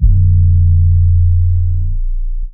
Teck-808 (redlight).wav